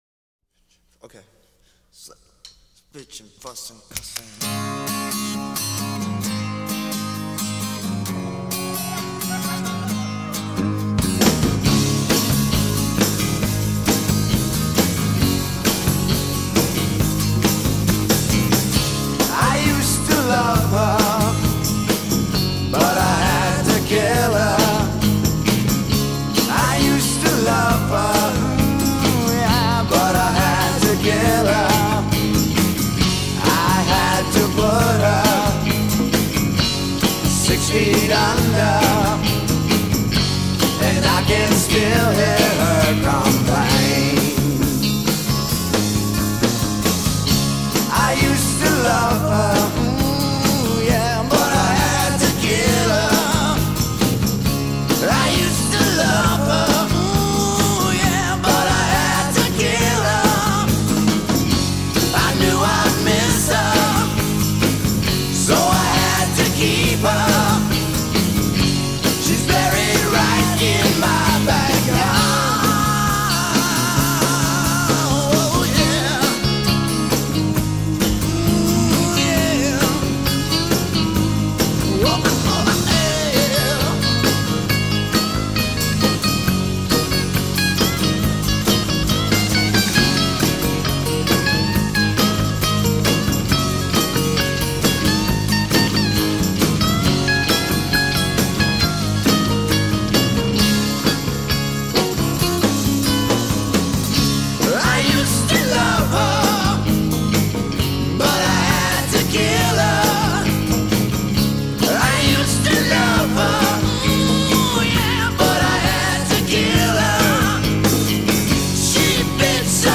2024-03-03 12:56:40 Gênero: Rock Views